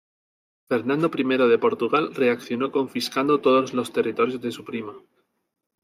Pronounced as (IPA) /ˈpɾima/